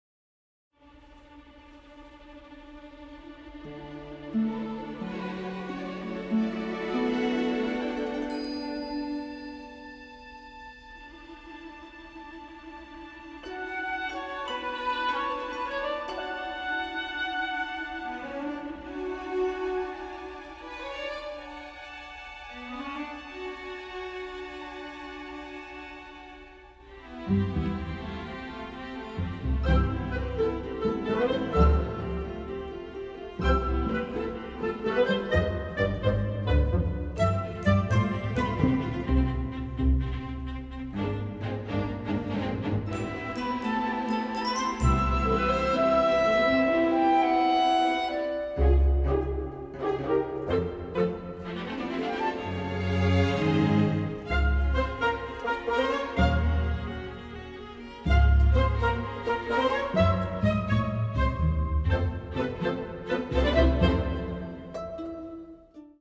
heartfelt orchestral score